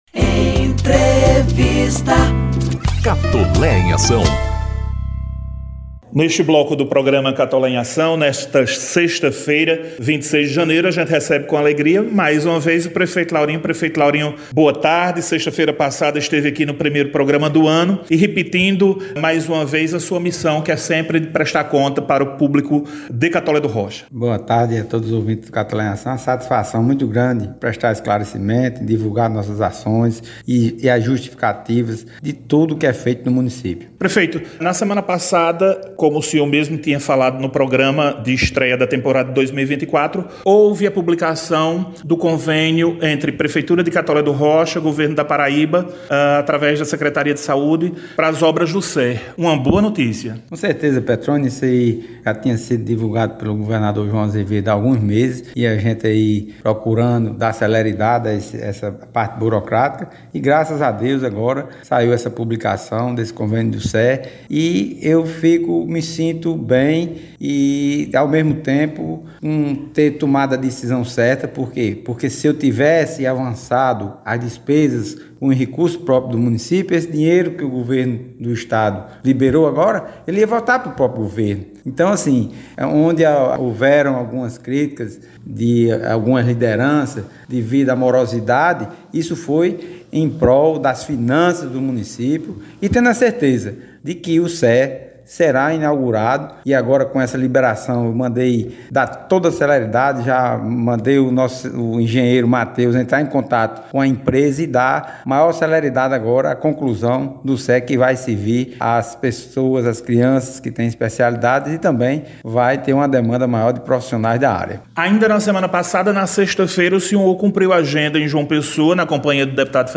Ouça a participação do Prefeito Laurinho Maia durante o Programa Catolé em Ação na sexta-feira, 26 de janeiro de 2024.